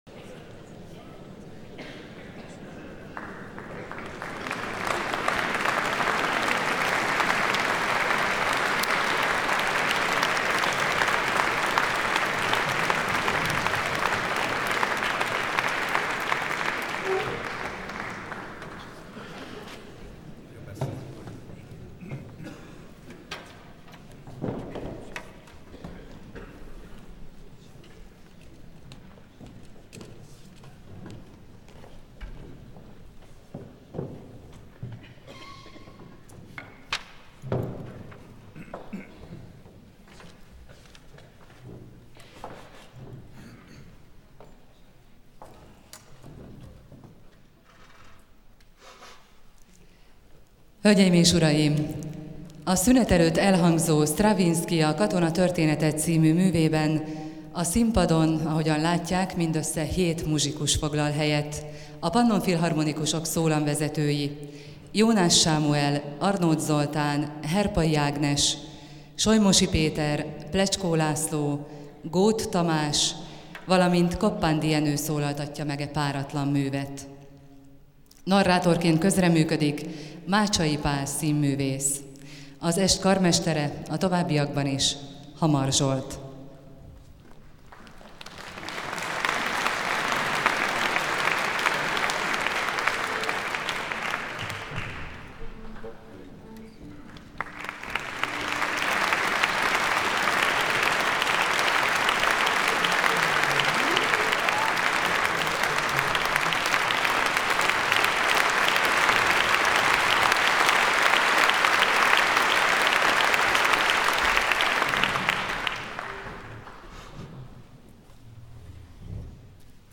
Stravinsky a katona története Műpa Live concert Hamar Zsolt